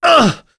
Gau-Vox_Damage_01.wav